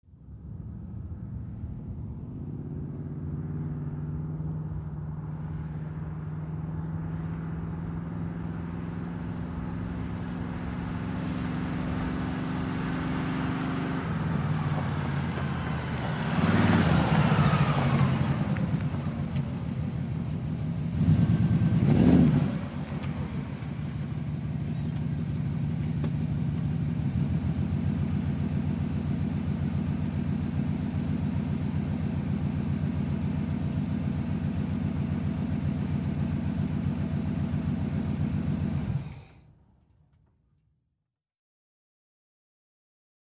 1958 Chevrolet Impala, In Fast, Stop Cu, Idle, Off.